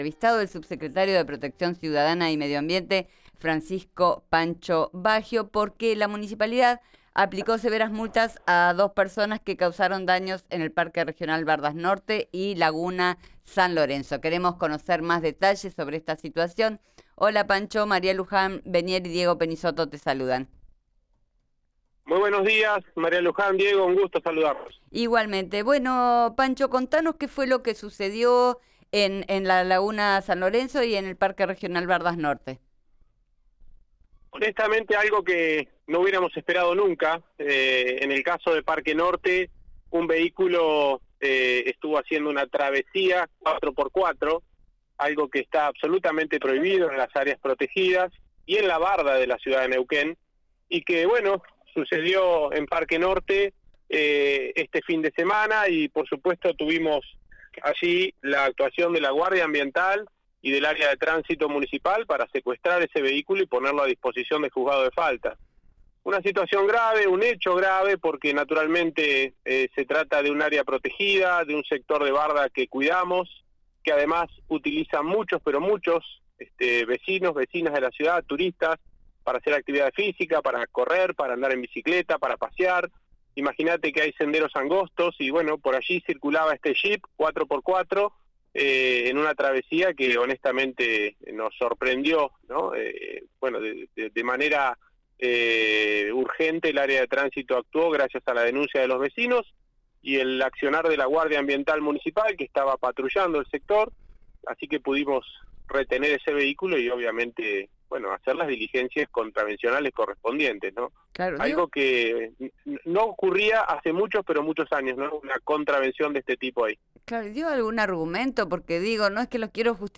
Escuchá a Francisco Baggio en RIO NEGRO RADIO: